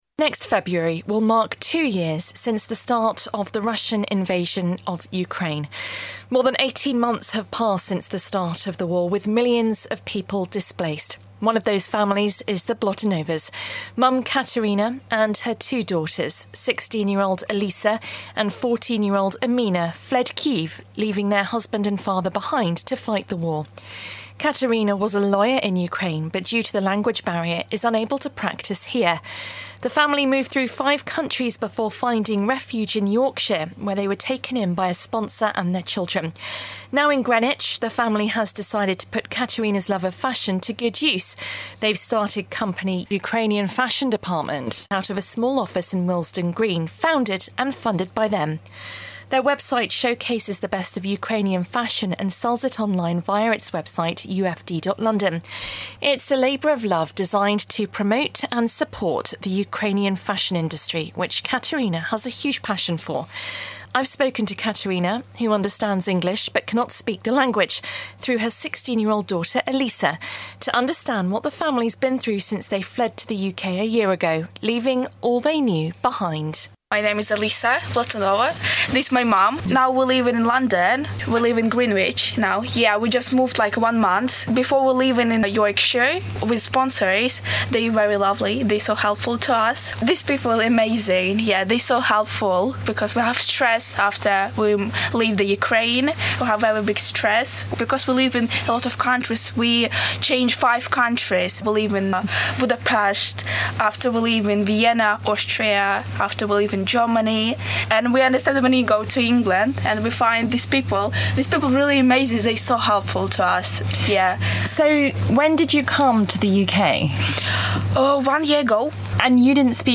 interview with Ukrainian family